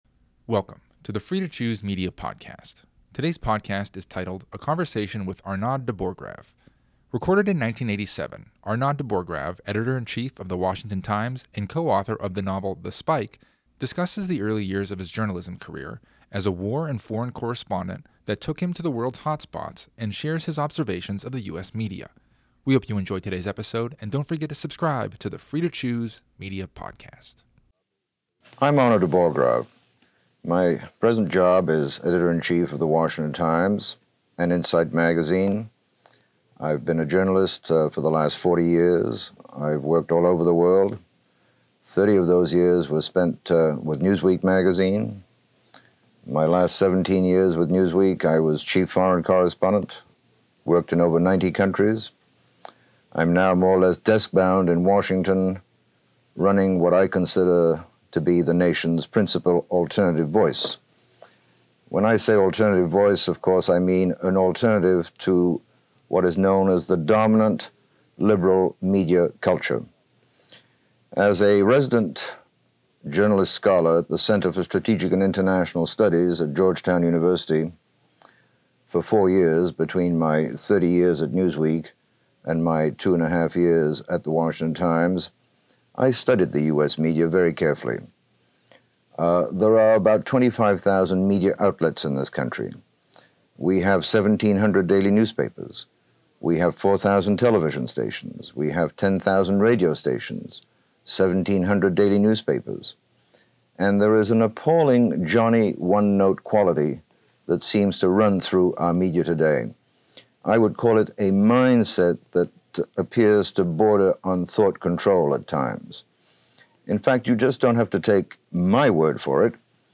Episode 230 – A Conversation with Arnaud de Borchgrave